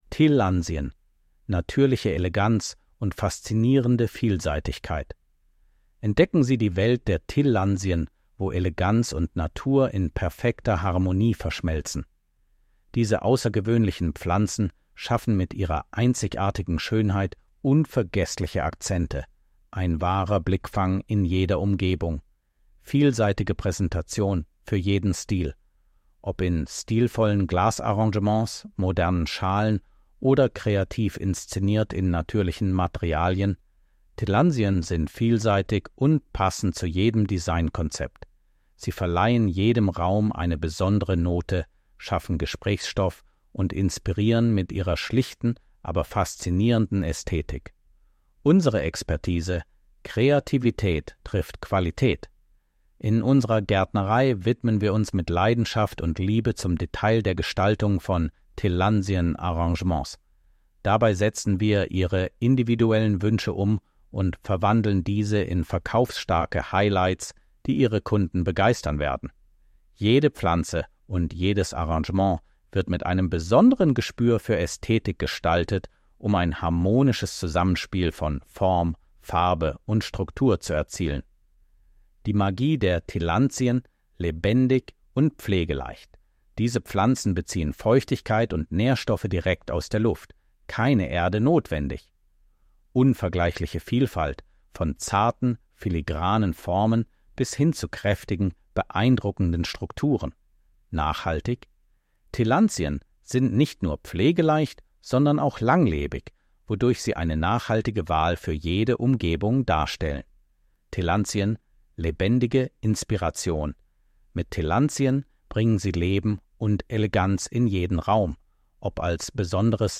Audio-Datei mit vorgelesenem Text über die Gärtnerei Hohn.